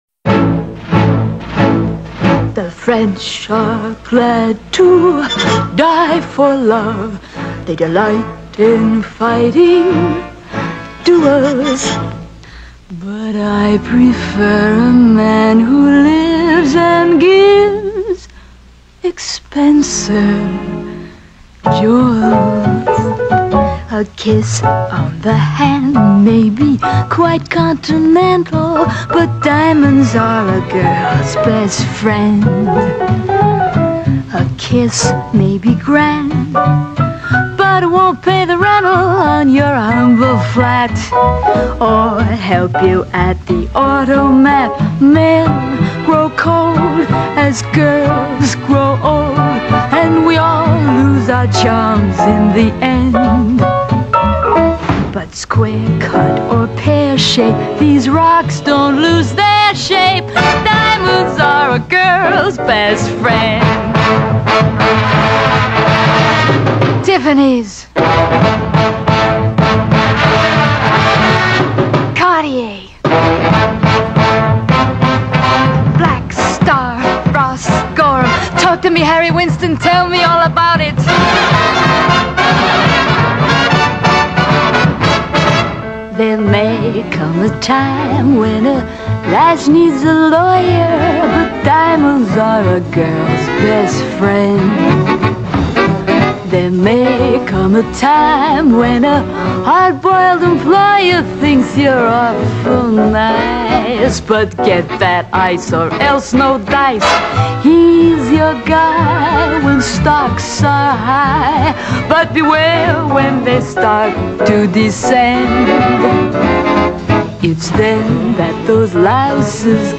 Pop, Jazz